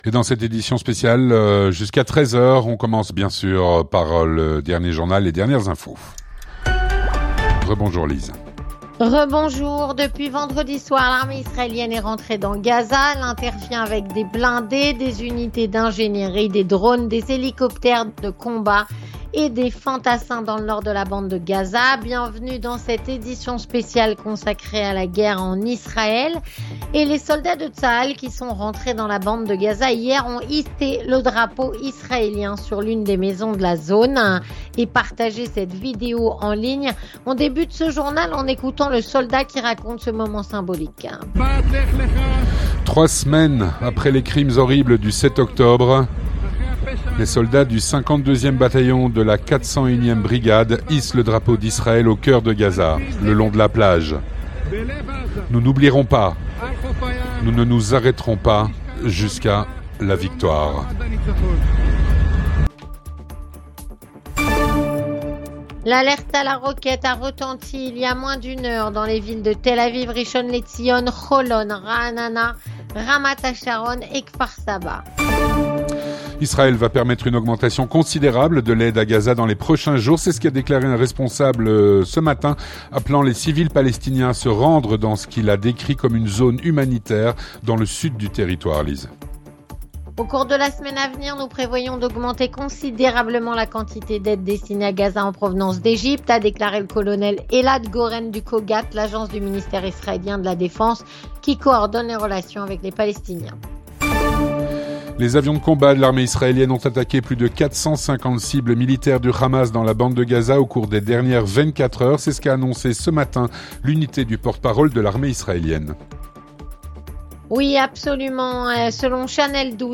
Avec - S.E. Idith Rosenzweig-Abu, ambassadrice d’Israël en Belgique